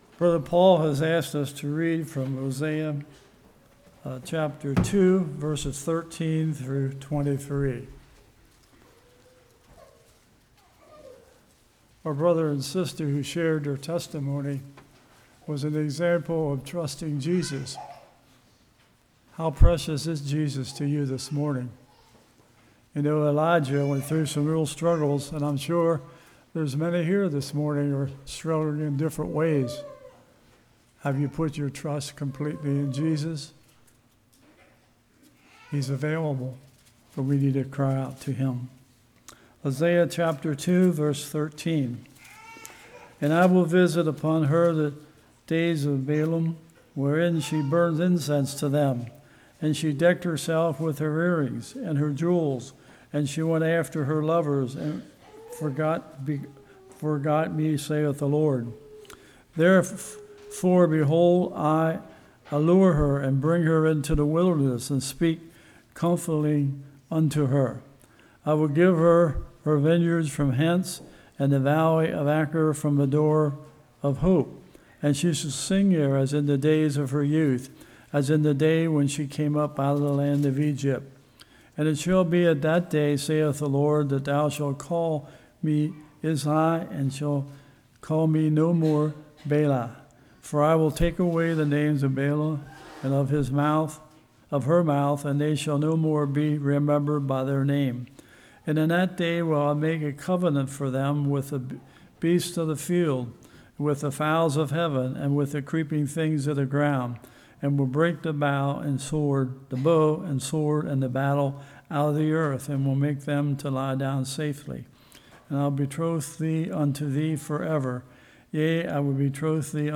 Hosea 2:13-23 Service Type: Morning God’s Love Redemption Grace Door of Hope « Brevity of Life